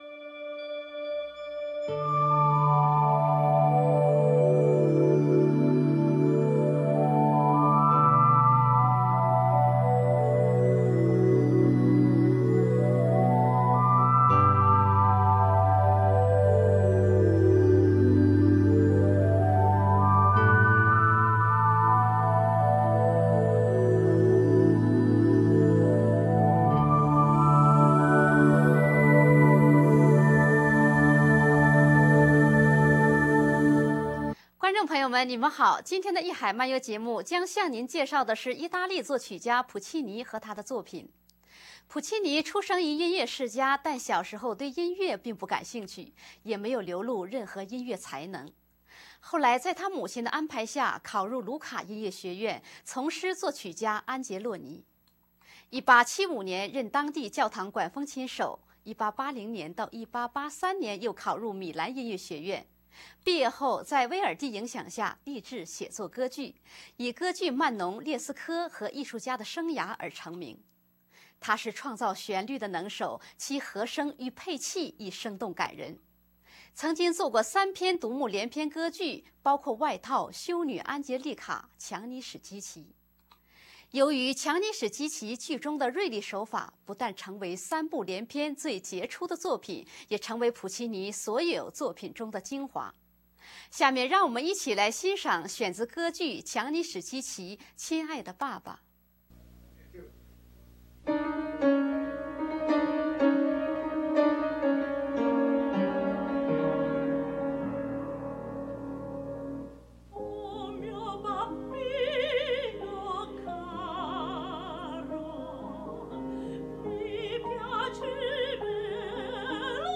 他是創造旋律的能手.其和聲與配器亦生動感人.